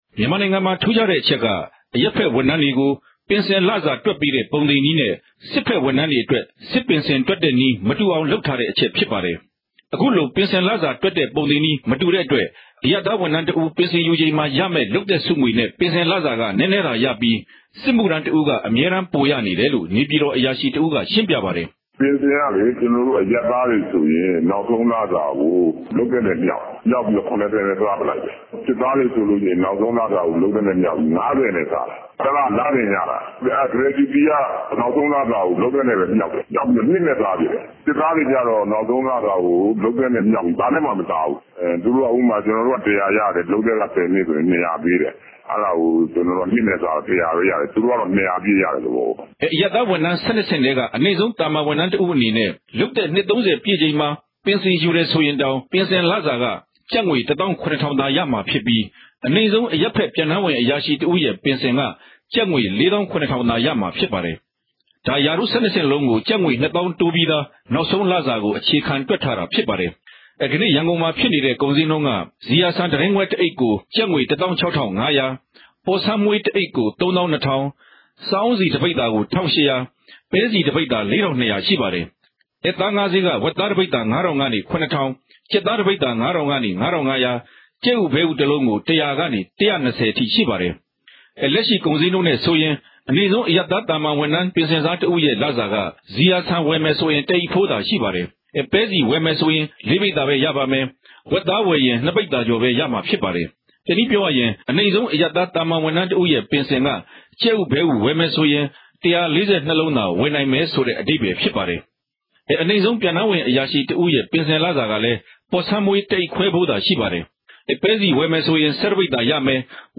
သုံးသပ်တင်ပြချက်။